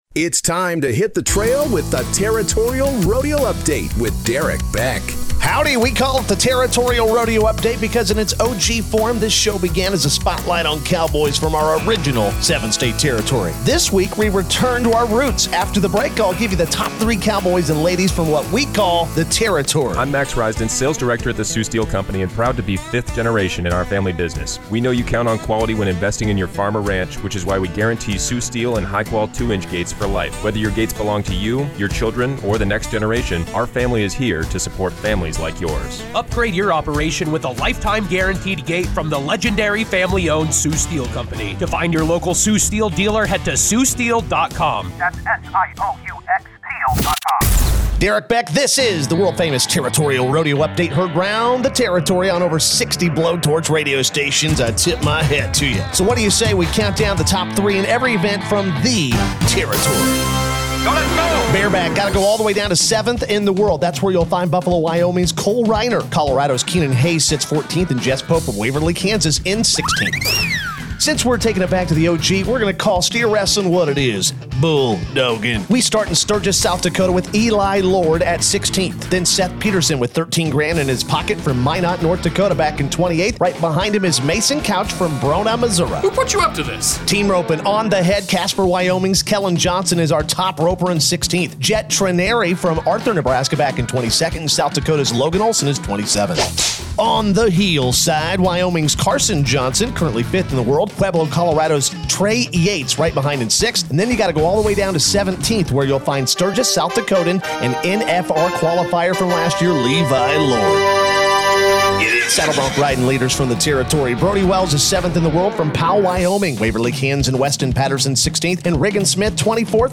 Broadcast across more than 60 radio stations and streamed online, the weekly program revisited its roots by highlighting the top three competitors from “the Territory” in each major rodeo event — a nod to the athletes who helped build the show’s identity.